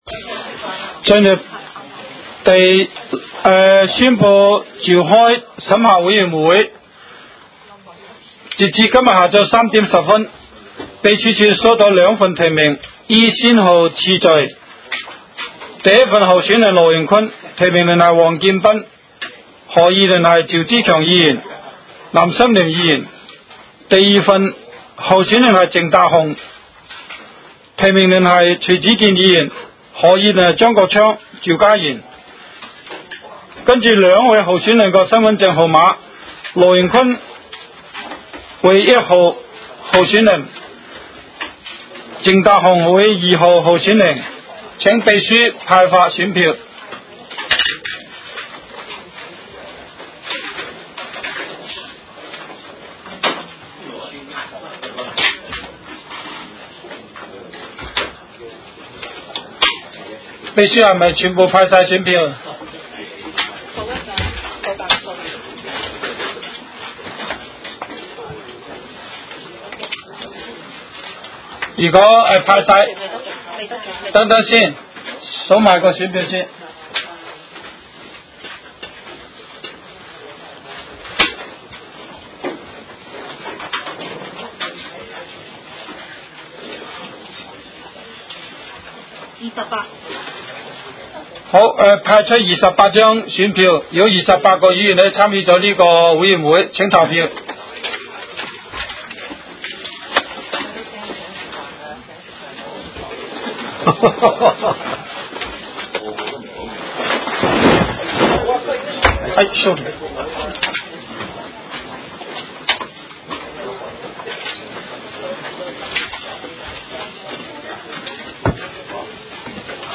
委员会会议的录音记录
审核委员会第一次会议 日期: 2016-01-05 (星期二) 时间: 下午4时10分 地点: 香港西湾河太安街29号 东区法院大楼11楼 东区区议会会议室 议程 讨论时间 I II 选举委员会会主席及副主席 下次会议日期 0:12:05 全部展开 全部收回 议程:I II 选举委员会会主席及副主席 下次会议日期 讨论时间: 0:12:05 前一页 返回页首 如欲参阅以上文件所载档案较大的附件或受版权保护的附件，请向 区议会秘书处 或有关版权持有人（按情况）查询。